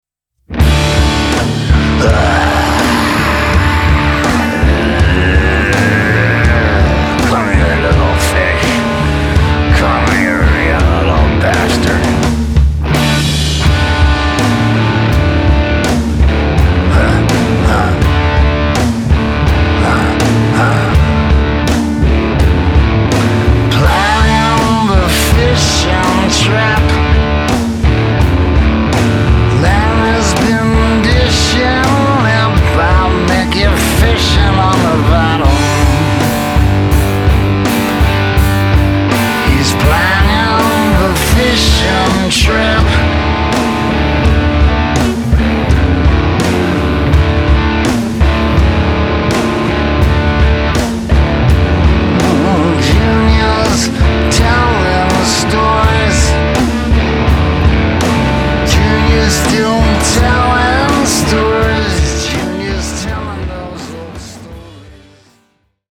drums, vocals